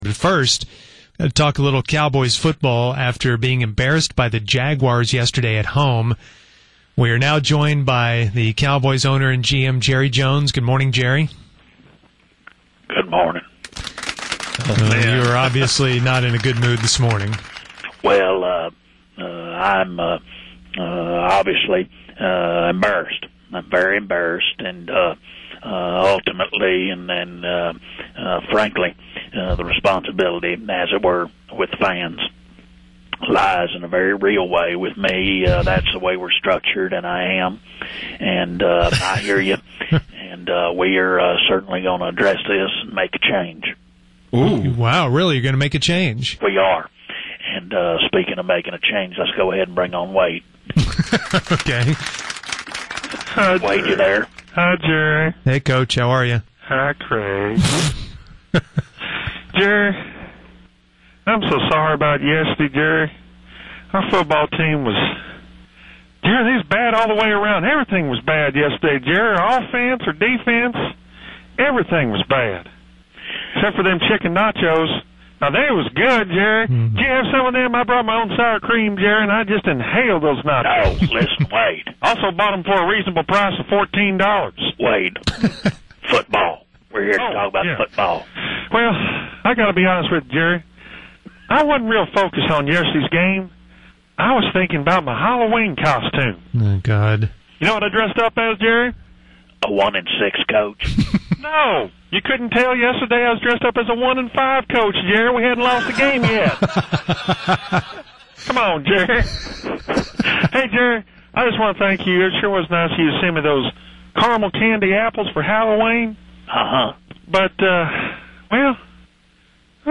The only positive thing about that abomination out in Arlington that we used to call the Dallas Cowboys is the Fake Wade and Fake Jerry press conference.